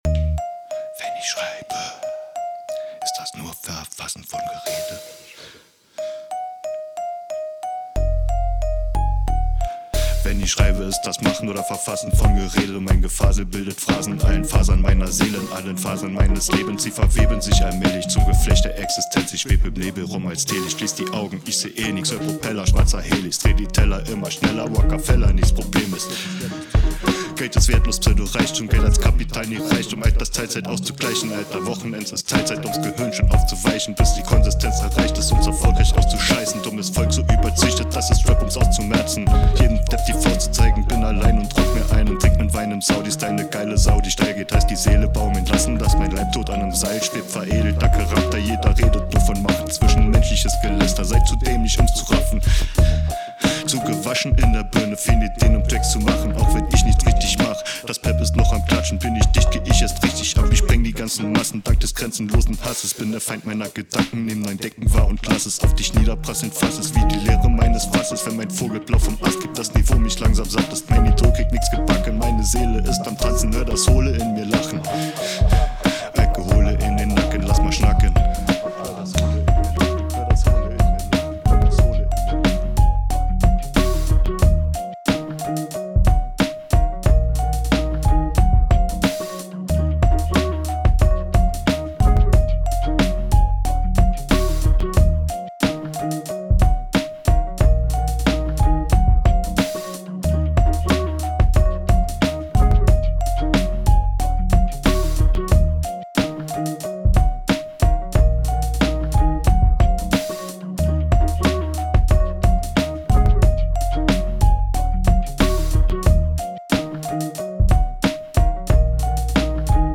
Rap Beat Libary?!
Was Dir fehlt ist die Vocal btw der Bezug und Aufbau zu einer.